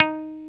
Index of /90_sSampleCDs/Roland L-CD701/GTR_Dan Electro/GTR_Dan-O 6 Str